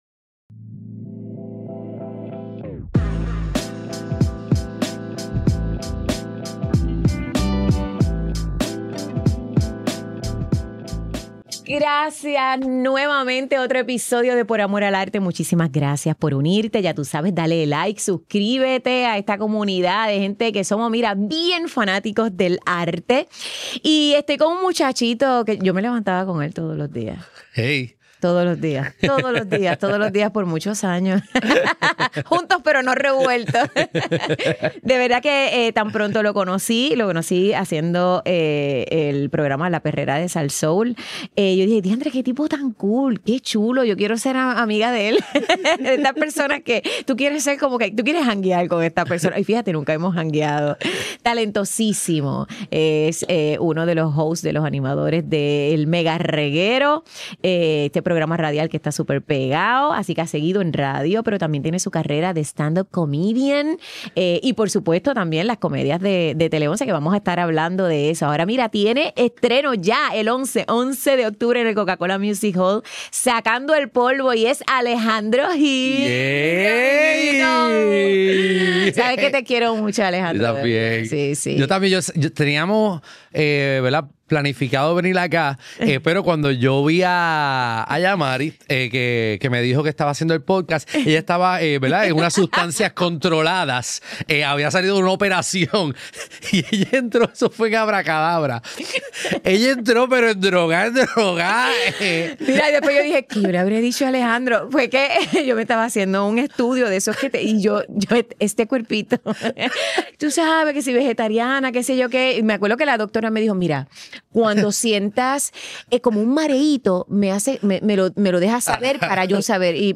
¡No te pierdas esta entrevista! Grabado en GW-Cinco Studio para GW5 Network Stylist